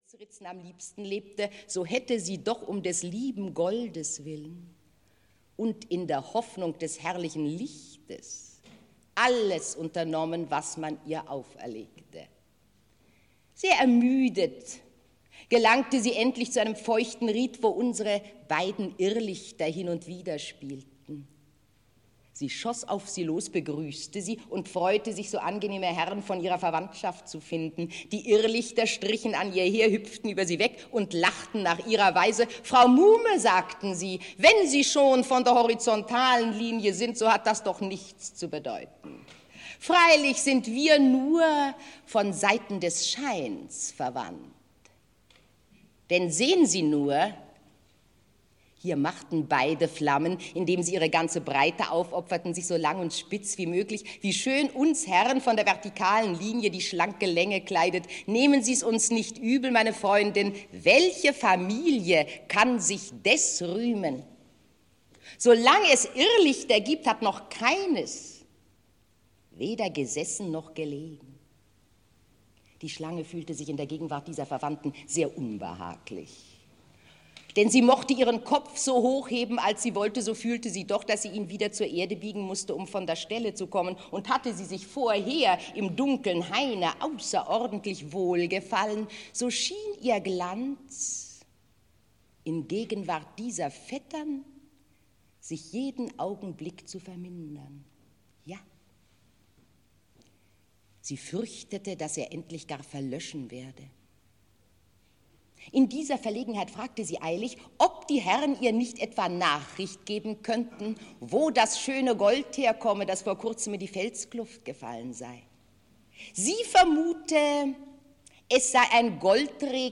Das Märchen - Johann Wolfgang von Goethe - Hörbuch